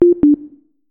beep_boop.mp3